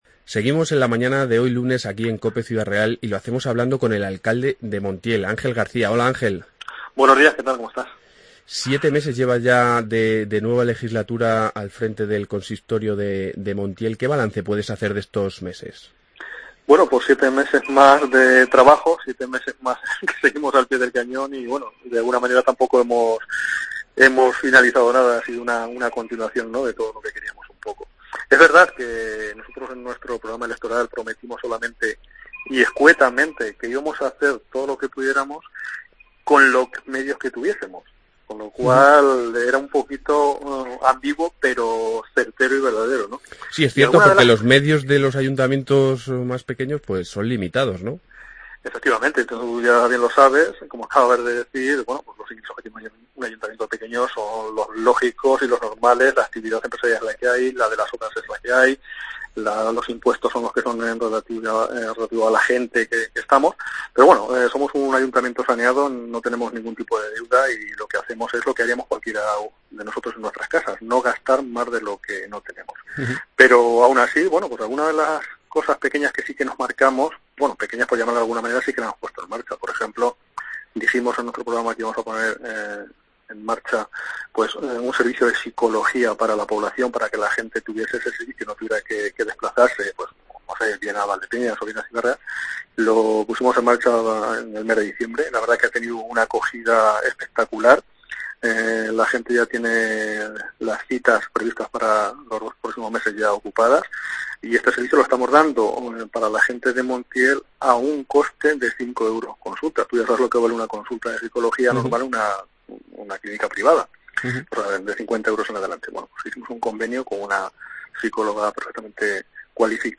AUDIO: Entrevista alcalde Montiel, Ángel García